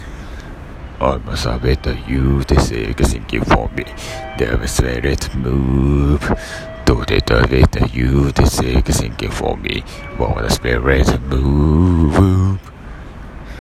lowCとかlowBがサクッとだせるようになったのです！
しこたま飲んだ後なので歌詞や音程ひっどいですが・・・。